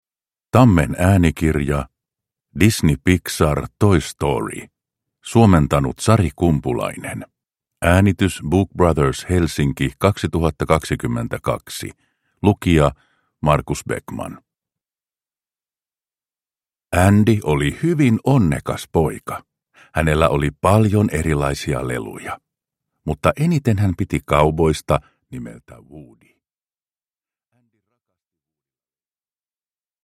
Toy Story – Ljudbok – Laddas ner
Ahmaise animaatiosuosikki hauskana äänikirjana!